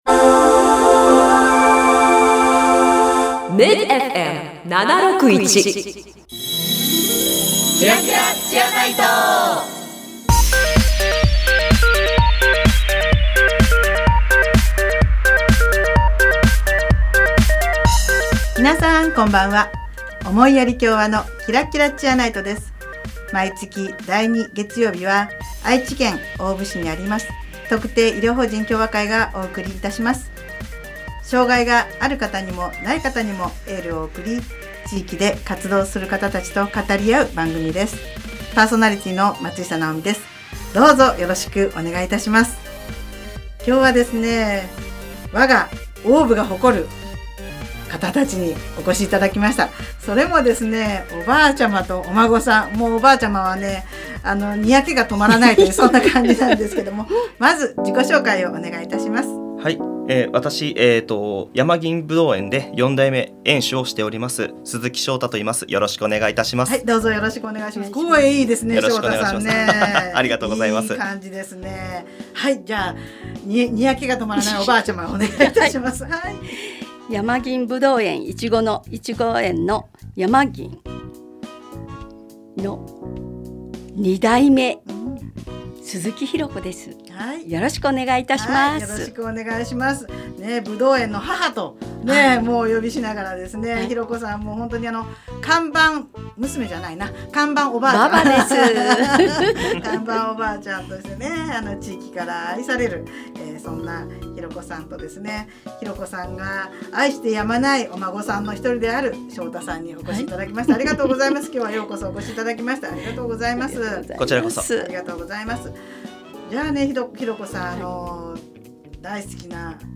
【放送時間 】第2月曜日 19：00 MID-FM 76.1 【ゲスト】
この番組では、地域の医療・福祉に携わる方々と語り合い、偏見にさらされやすい障がいのある方に心からのエールを送ります。 毎回、医療・福祉の現場に直接携わる方などをゲストに迎え、現場での色々な取り組みや将来の夢なども語り合います。